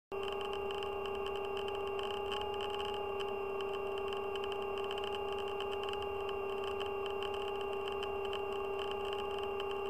Knack-Geräusch bei BLDC
Motor_Knacken.mp3